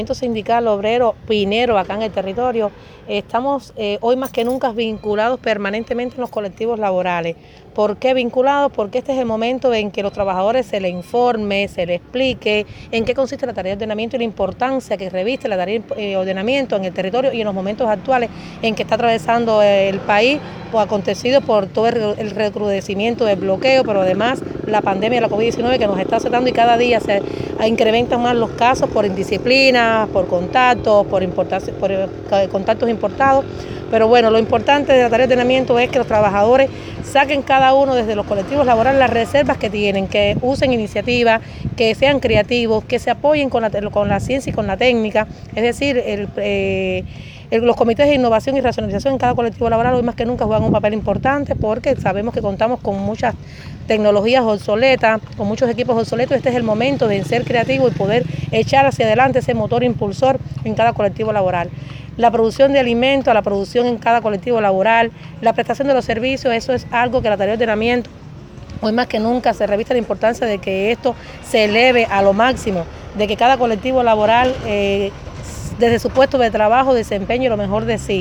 Entrevistas Isla de la Juventud Ordenamiento